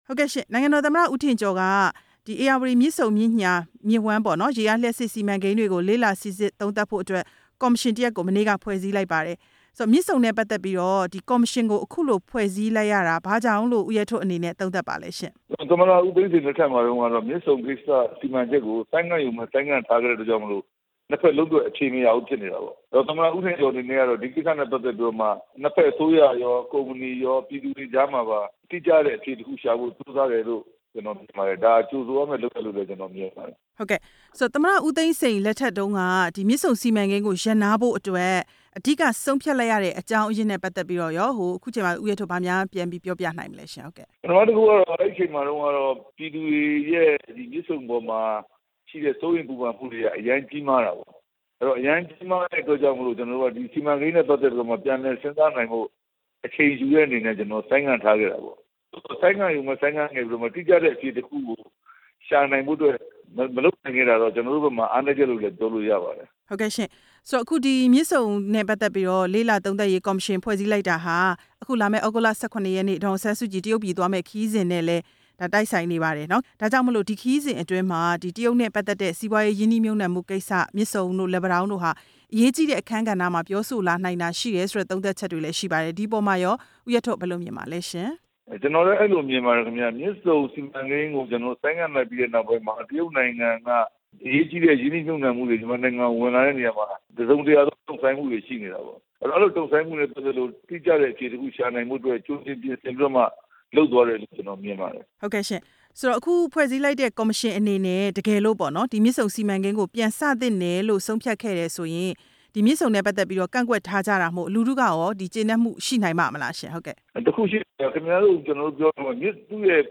မြစ်ဆုံစီမံကိန်းကော်မရှင်သစ်အပေါ် ဝန်ကြီးဟောင်း ဦးရဲထွဋ်နဲ့ မေးမြန်းချက်